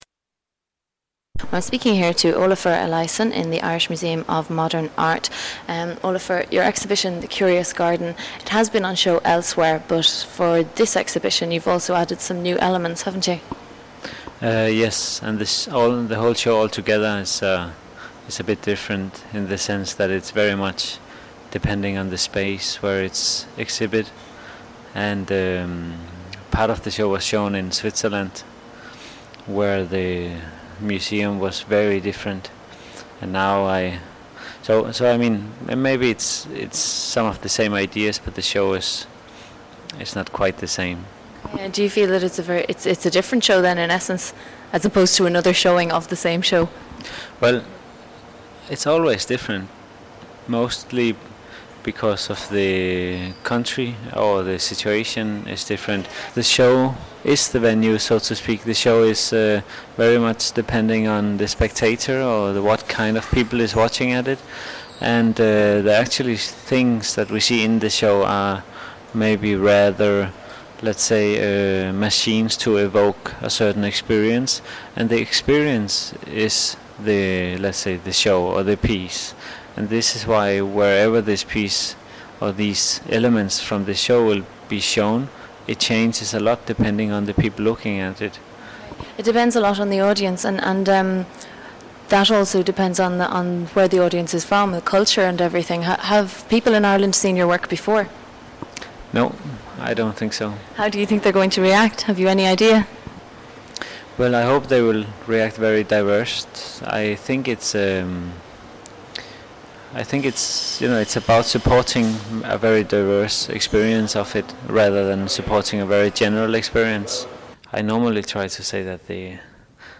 Interview with Olafur Eliasson, 2000